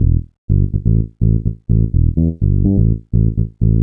cch_bass_loop_joy_125_Gm.wav